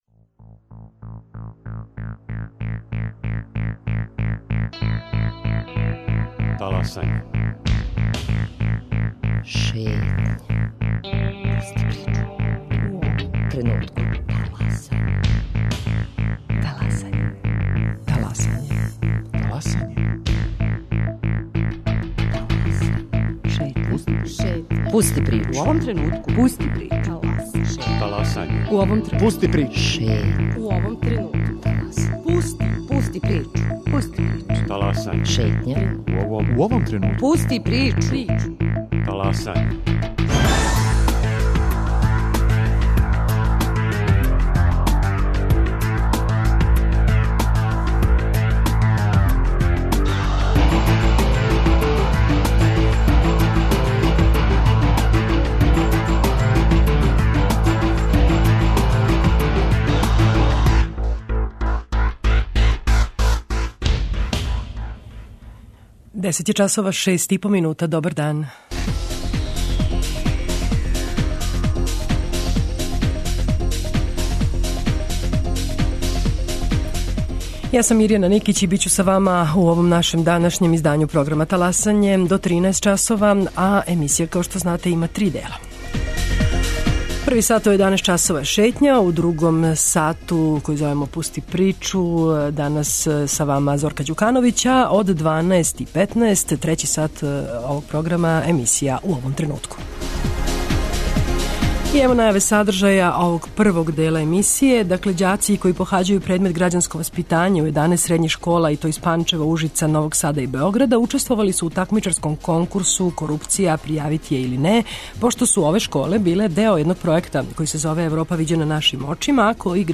Чућемо размишљања награђених средњошколаца на ову тему.